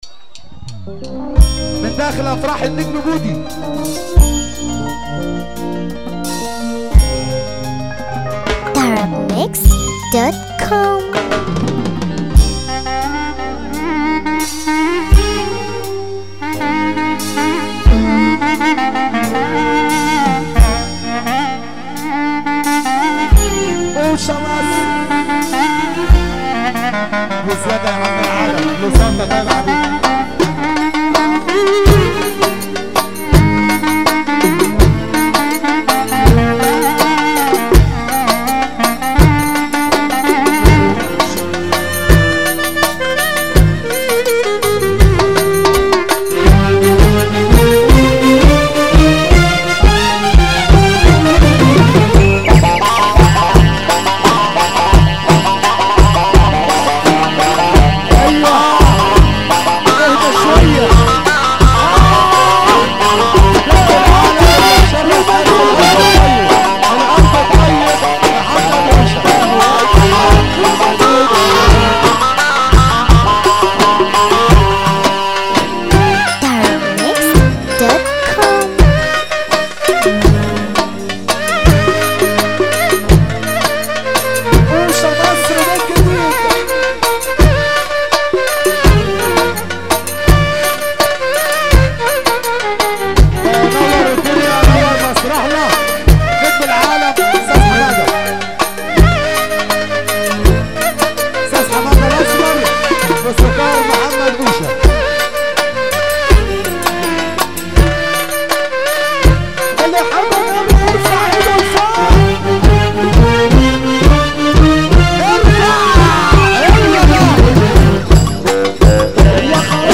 مزمار